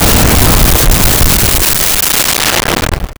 Explosion 03
Explosion 03.wav